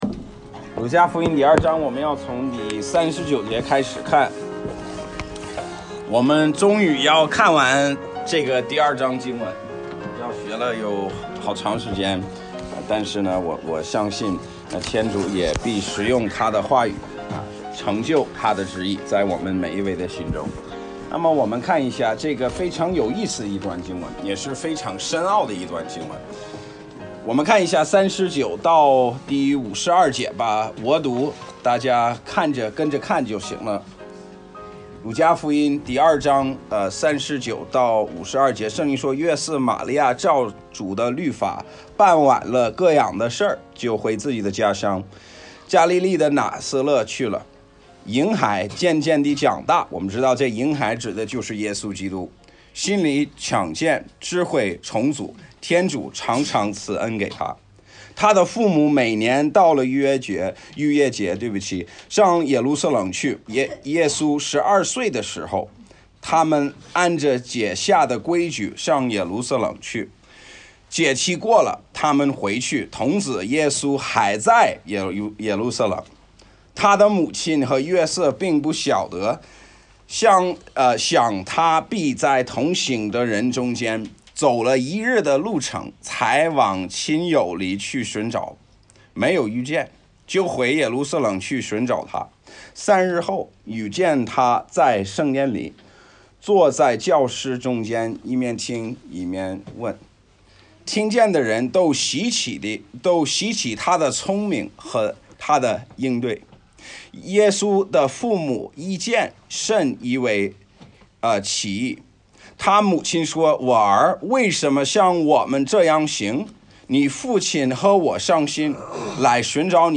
Bible Text: 路加福音2章39-52节 | 讲道者